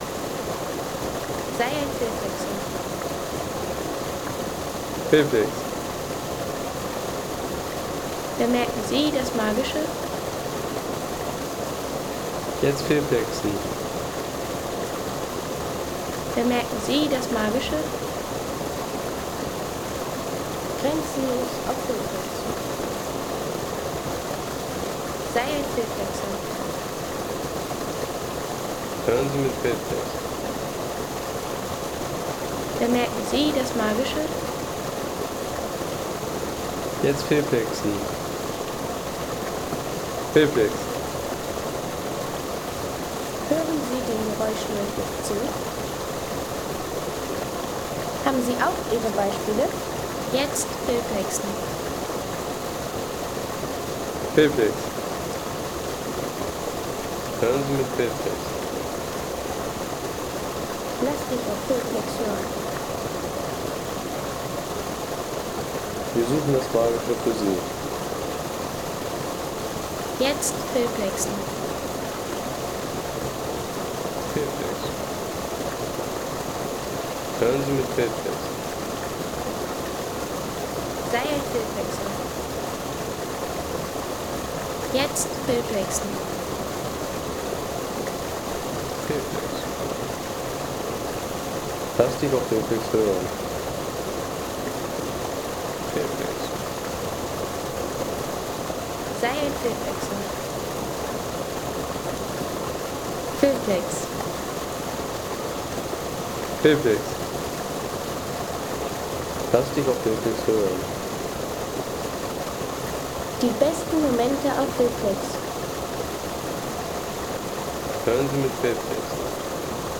Wasserbecken der Stuibenfälle